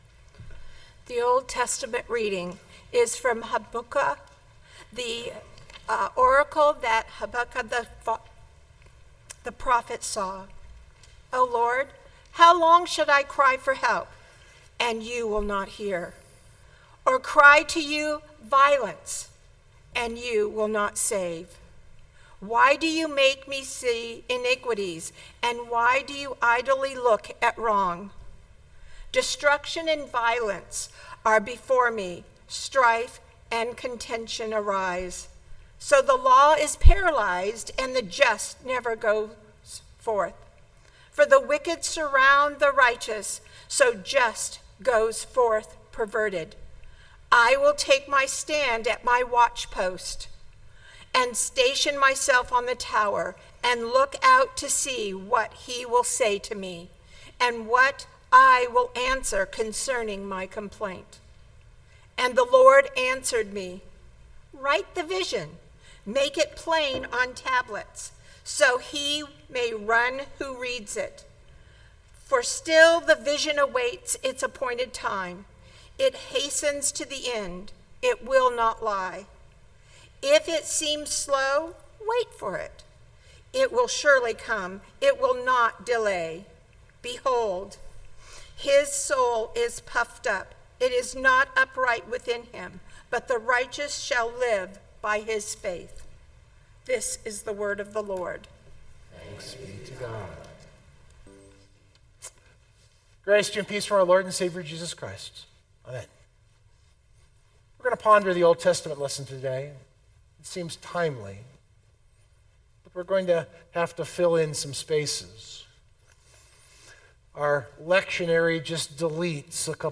This sermon sets Habakkuk’s story in the light of Adam, Eve and the Old Snake.